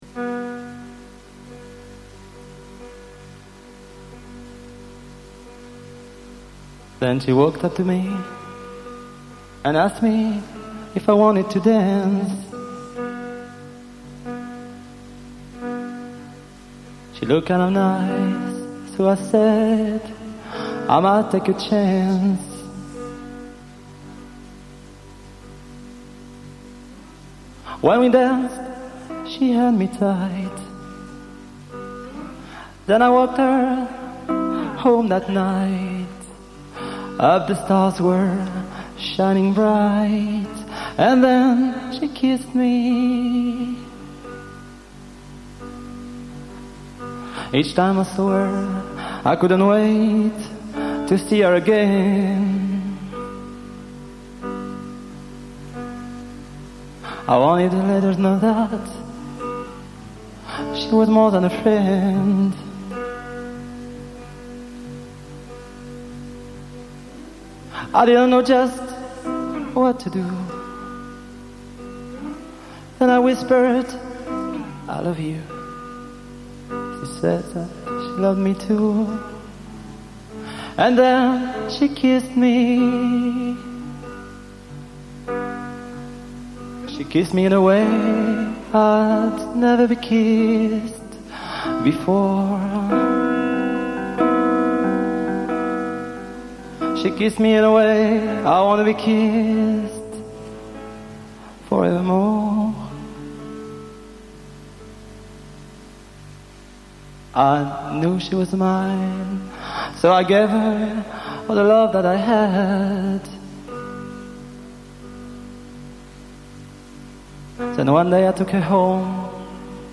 enregistrée le 04/02/1994  au Studio 105